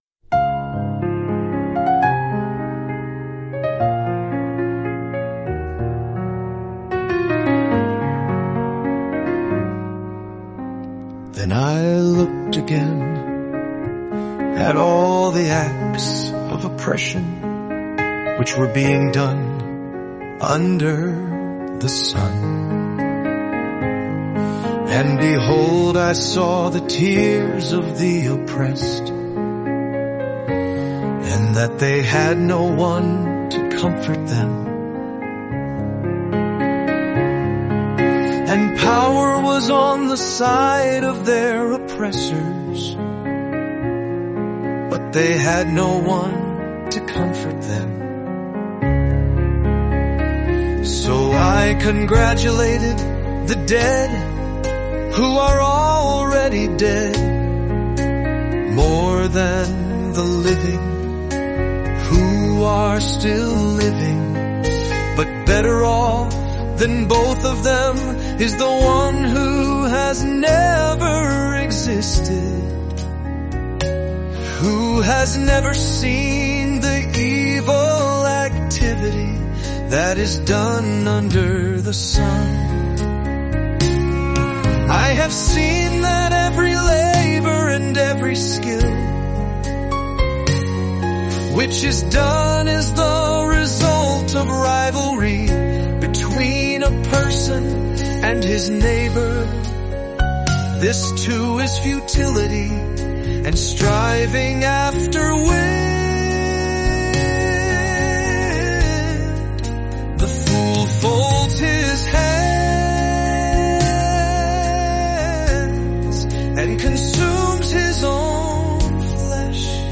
Experience the beauty and wisdom of Song of Songs and Ecclesiastes in just 7 days through word-for-word Scripture songs. Each day, listen to passages that capture the depth of love, the pursuit of meaning, and the reflections of life under the sun—brought to life through music.